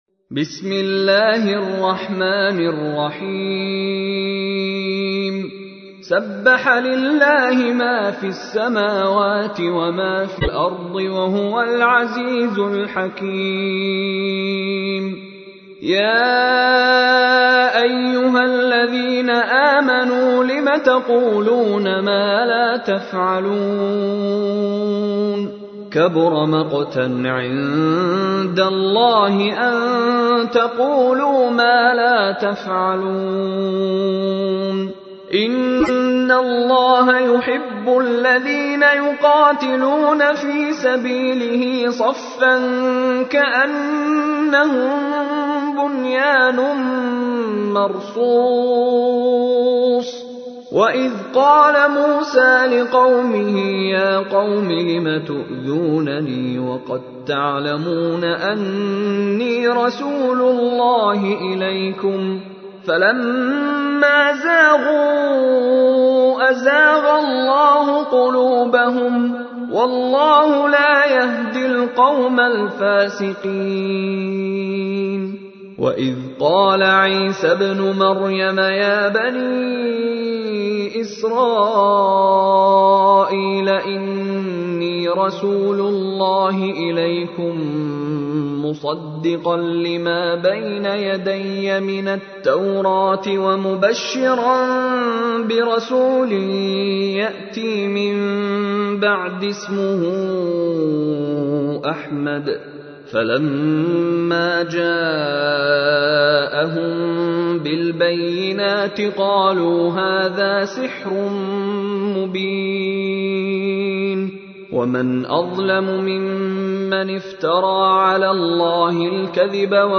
تحميل : 61. سورة الصف / القارئ مشاري راشد العفاسي / القرآن الكريم / موقع يا حسين